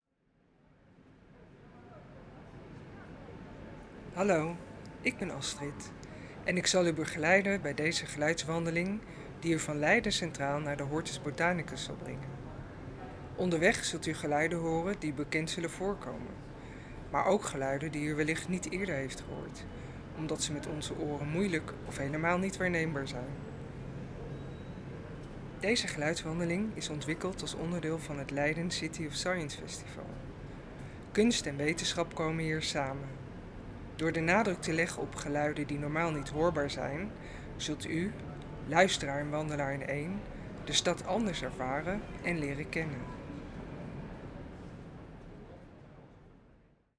Ongehoord Leiden laat je het Leidse stadscentrum opnieuw beleven met geluiden die normaal niet te horen zijn.
Audiowandeling, Nederlands.
De geluidswandeling Ongehoord Leiden nodigt je uit om ‘met andere oren’ te luisteren naar de geluiden van het stadscentrum van Leiden, onder andere door middel van opnametechnieken die onbekende aspecten ontsluiten van welbekende plekken of geluiden presenteren die meestal niet gehoord of niet meer gehoord kunnen worden.